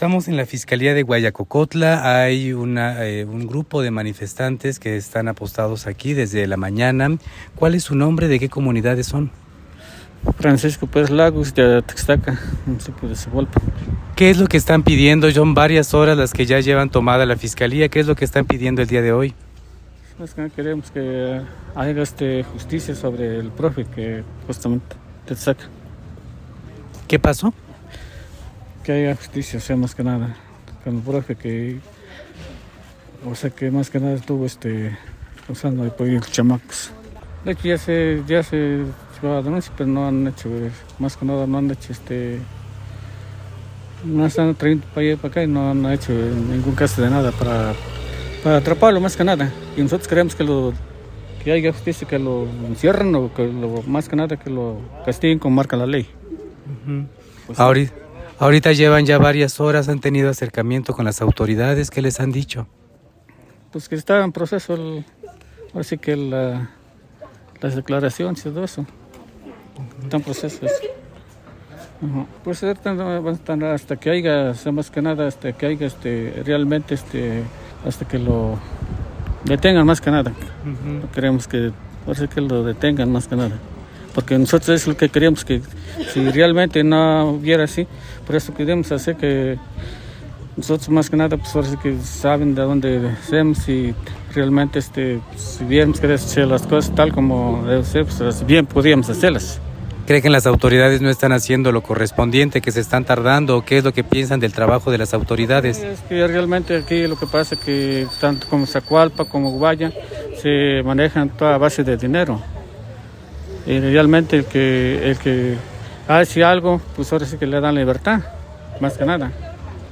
Manifestante-de-Atixtaca.mp3